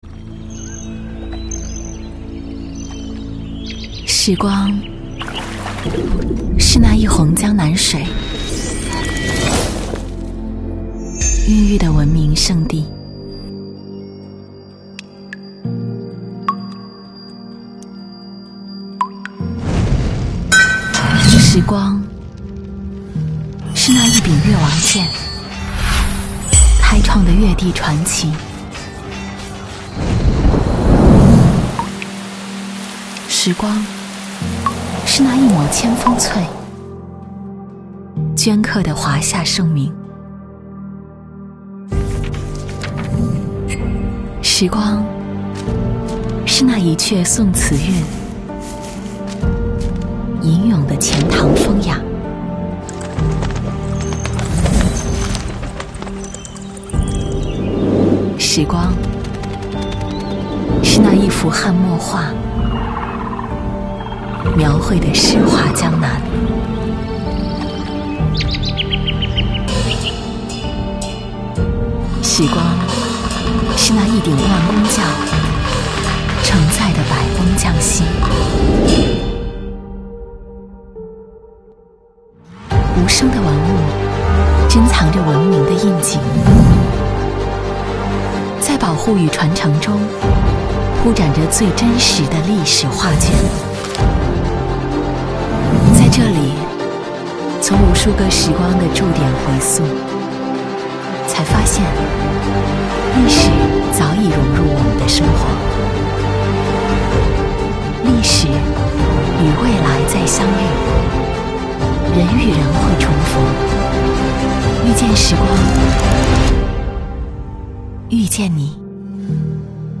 女29-宣传片-中国水周 纪录片
女29经铃抒情 v29
女29-宣传片-中国水周-纪录片.mp3